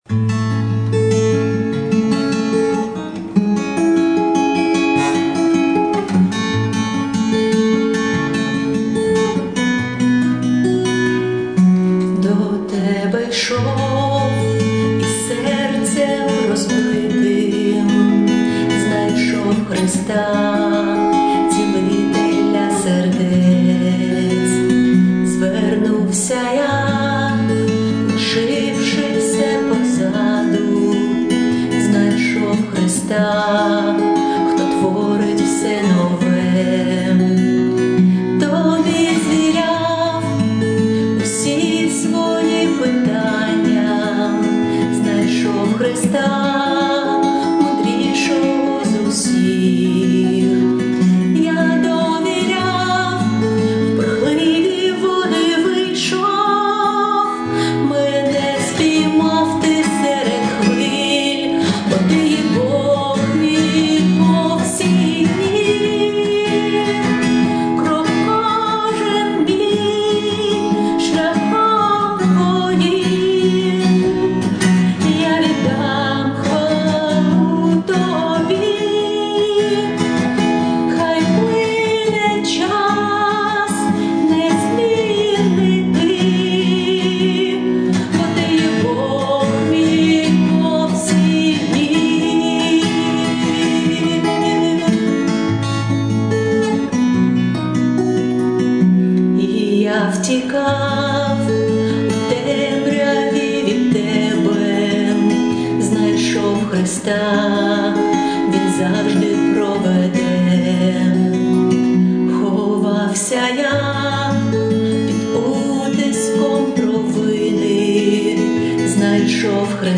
песня
319 просмотров 37 прослушиваний 0 скачиваний BPM: 140